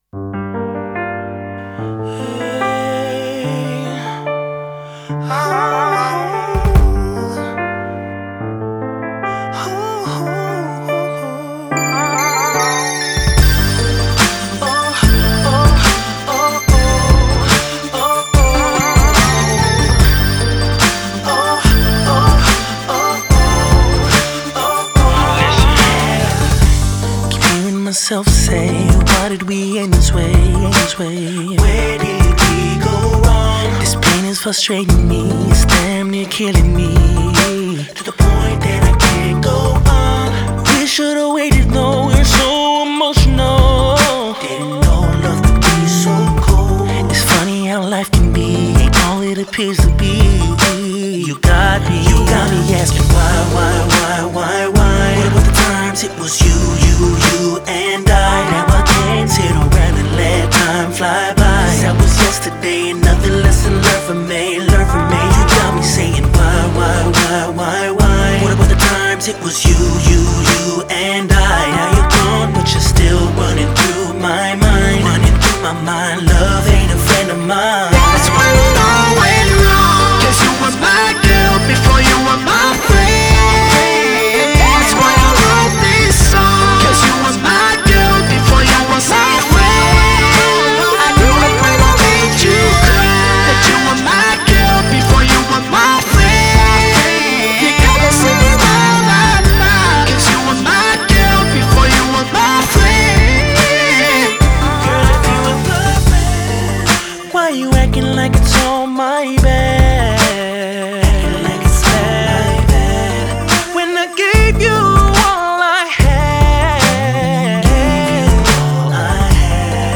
R&B, Hip Hop, Funk, Soul and Rock
talk-box skills that only get better by the song